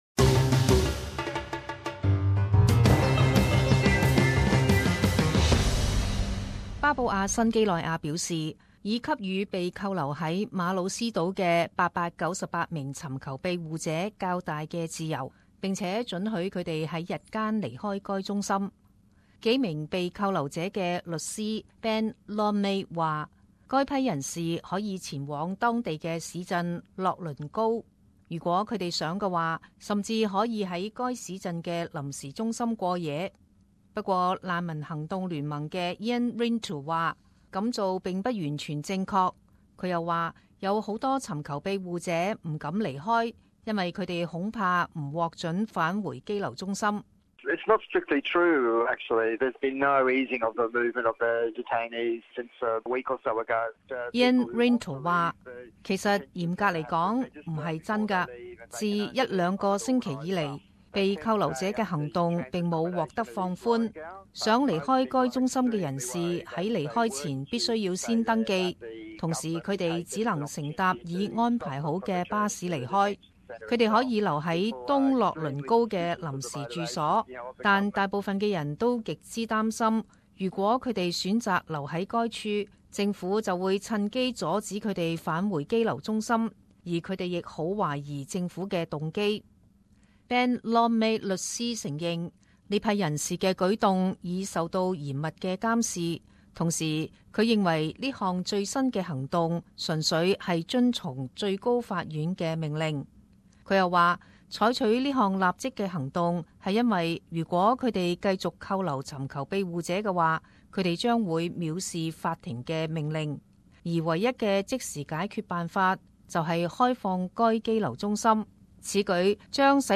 时事报导 - 马努斯岛被扣留者已获较大自由